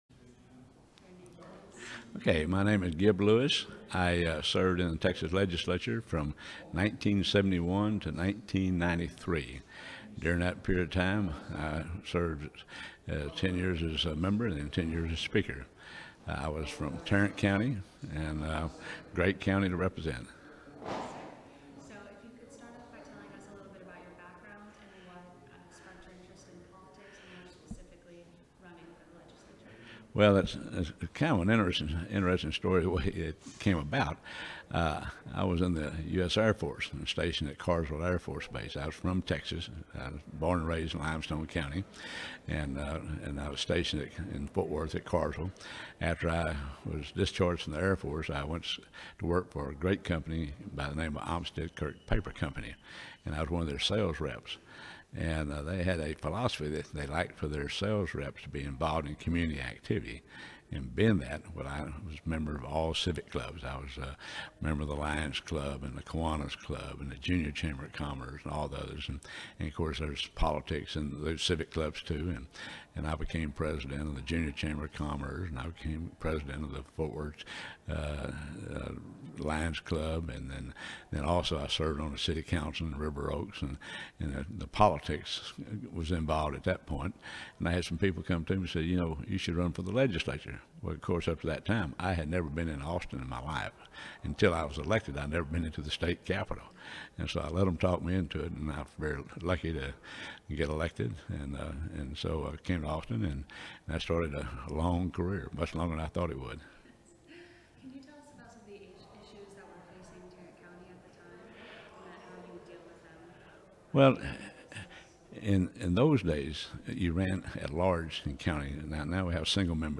Oral history interview with Gib Lewis, 2015.